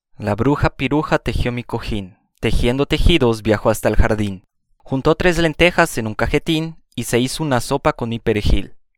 Trabalenguas com “r” e “j”
Em resumo, o “j” é pronunciado como o “rr” do português – assim, “la bruja” (“a bruxa”) soa como “la brurra”.